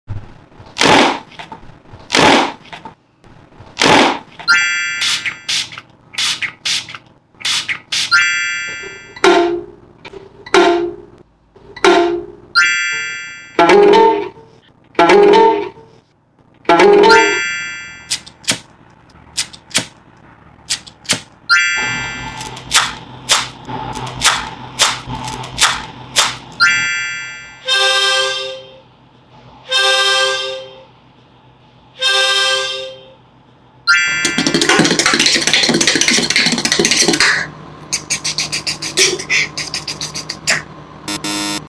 real sounds vs beatbox sounds sound effects free download